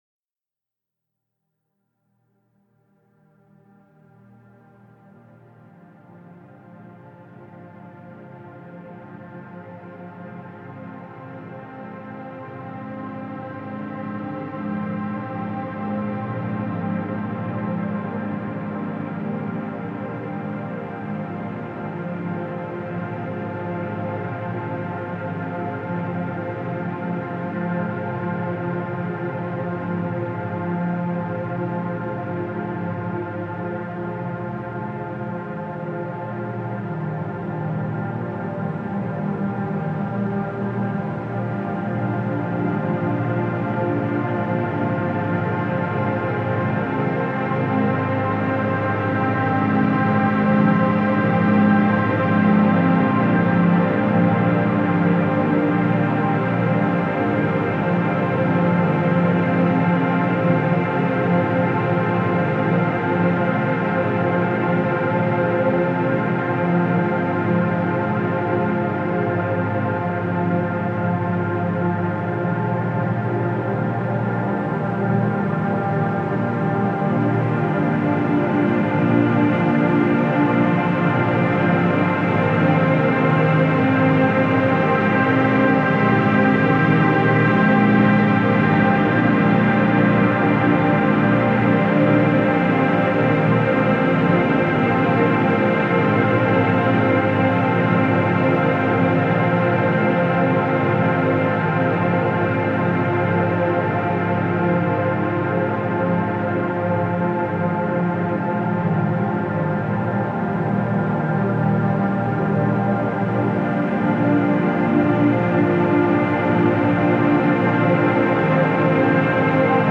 Genre: Ambient/Drone.